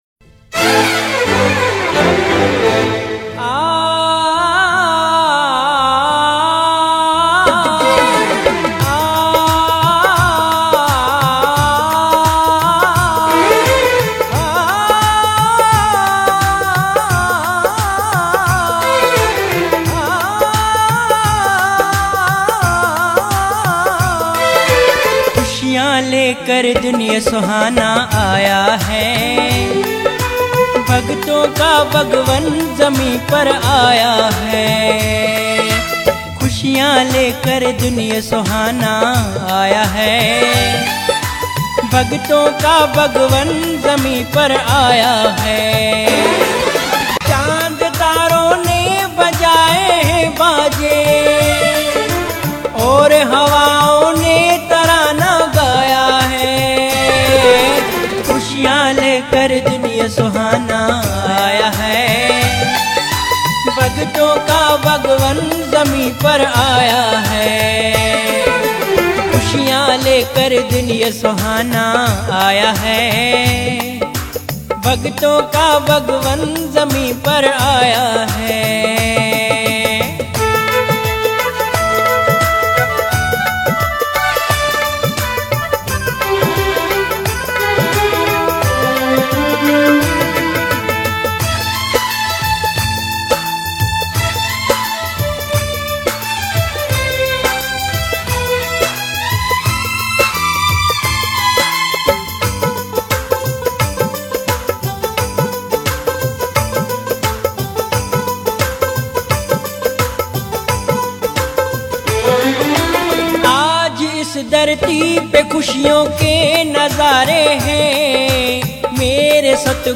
Bhajan 7- Khushian lekar din ye suhana aaya hai